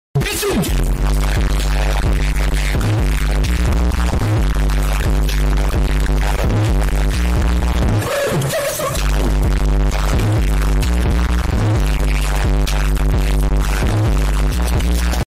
Kategori Ses Efektleri